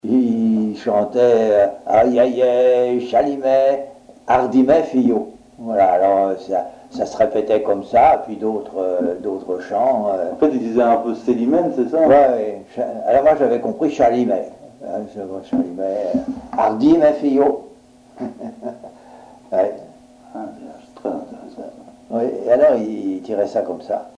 gestuel : à haler
circonstance : maritimes
Pièce musicale inédite